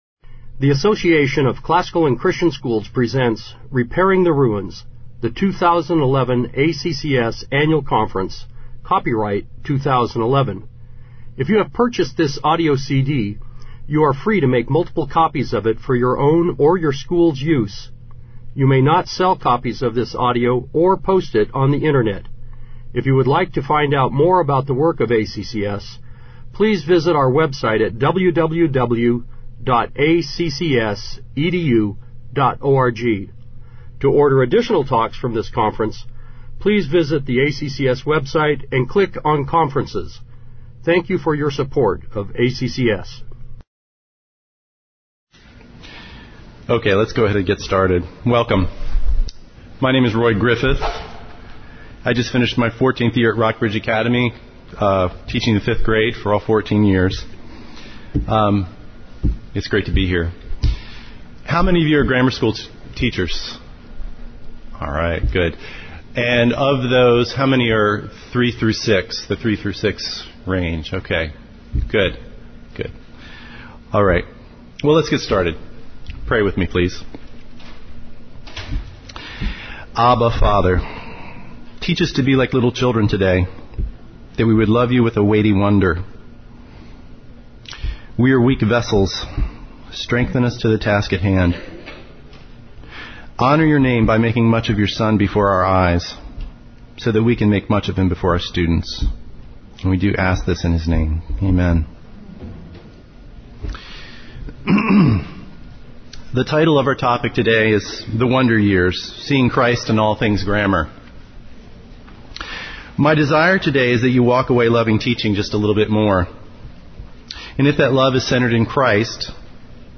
2011 Workshop Talk | 0:51:33 | K-6, General Classroom